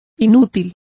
Complete with pronunciation of the translation of fruitless.